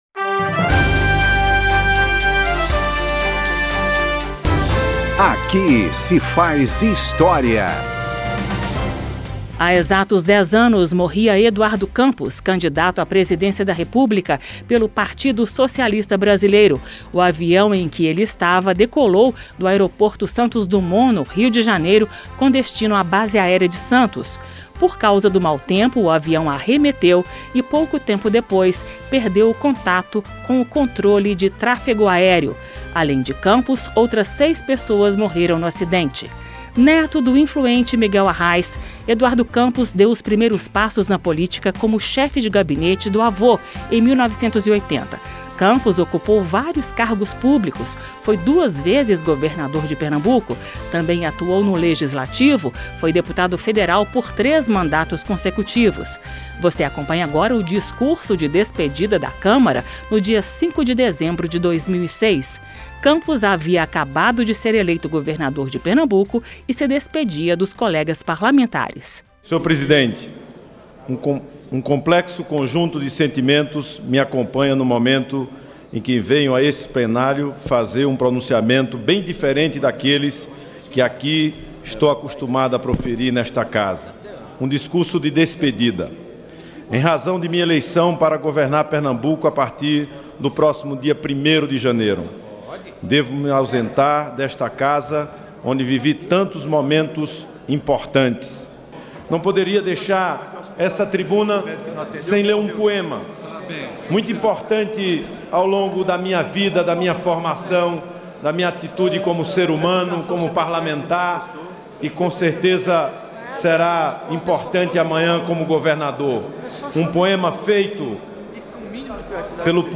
Em plena campanha presidencial, morria de acidente aéreo, Eduardo Campos. Ouça discurso em plenário de despedida da Câmara, ao ser eleito governador de Pernambuco.
Um programa da Rádio Câmara que recupera pronunciamentos históricos feitos no Parlamento por deputados ou agentes públicos, contextualizando o momento político que motivou o discurso.